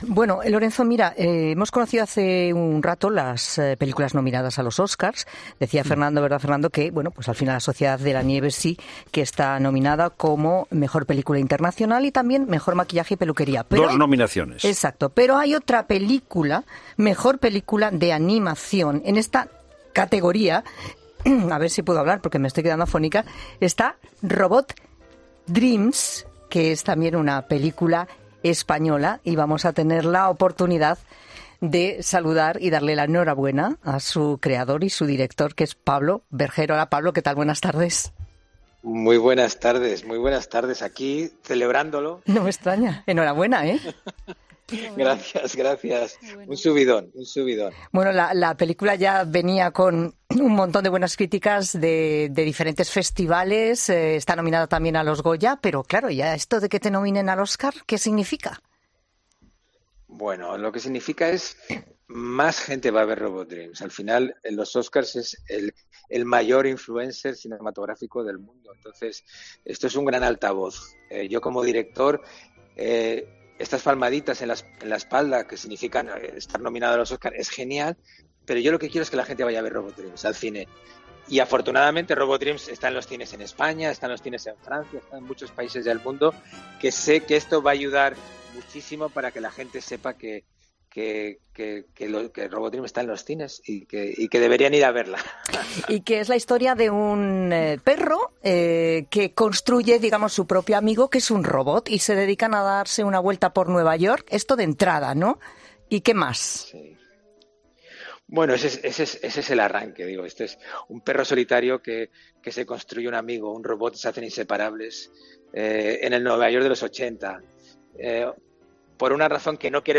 Pablo Berger, director de Robot Dreams, nominada a los Oscar, en 'La Tarde': "Es un gran altavoz"
Berger ha asegurado en 'La Tarde' que esta nominación significa que "más gente va a ver 'Robot Dreams'".